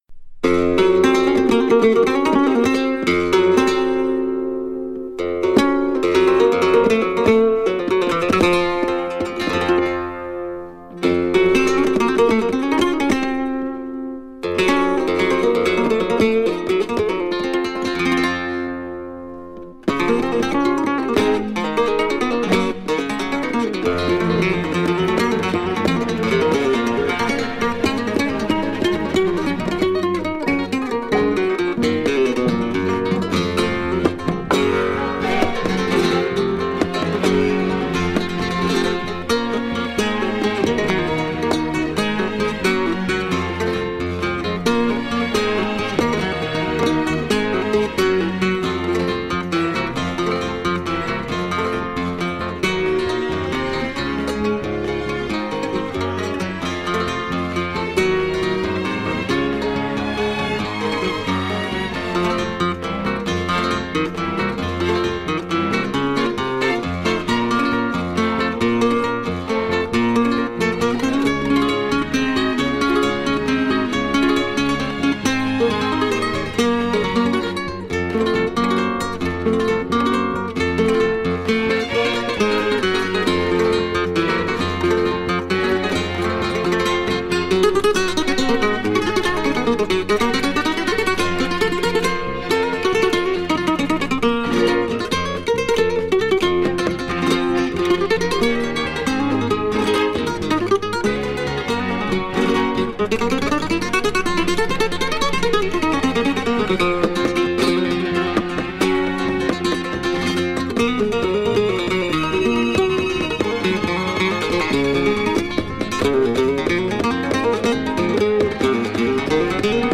bulerías